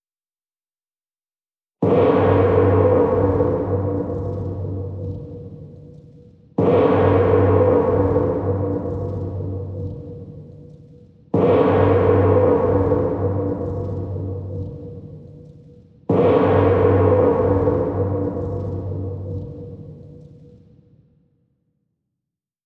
Convidamos você a desfrutar e usar em seus próprios rituais, os seguintes arquivos de som de gongo das Casas Negras passadas e presentes.
Brass Gong, Black House, California Street (quatro tempos)
BH6114gong.wav